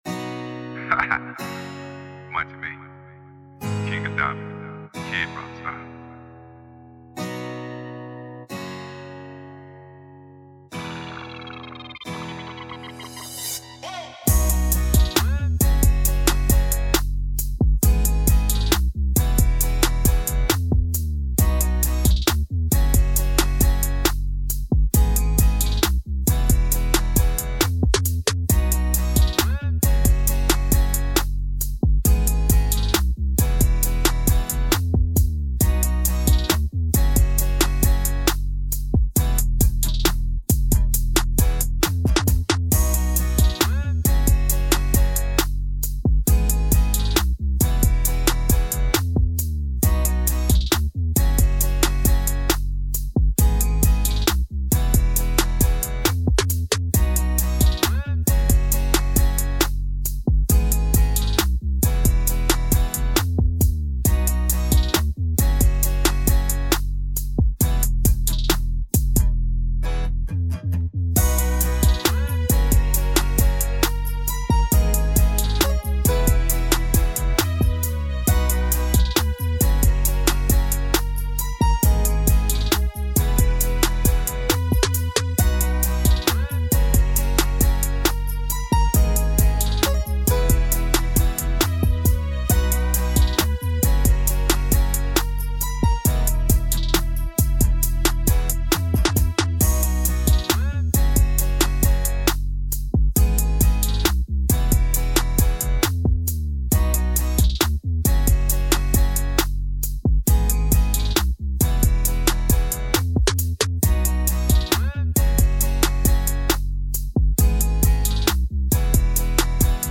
This is the official instrumental
Hip-Hop Instrumentals